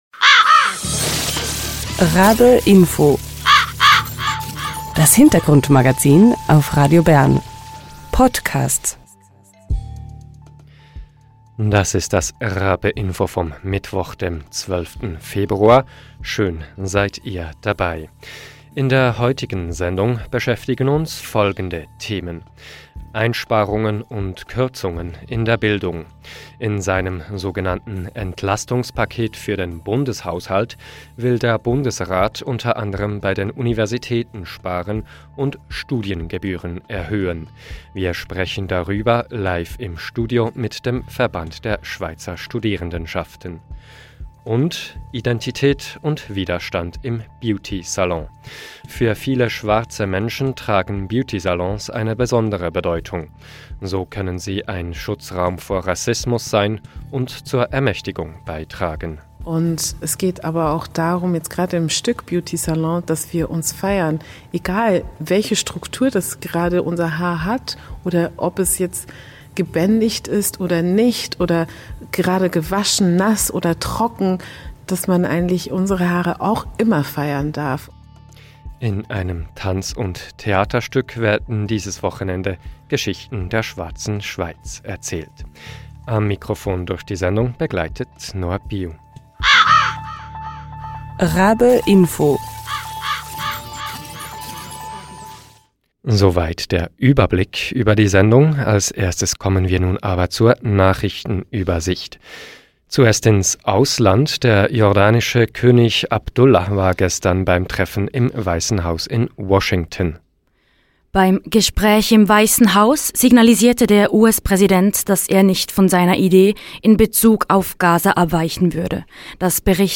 Wir sprechen darüber live im Studio mit dem Verband der Schweizer...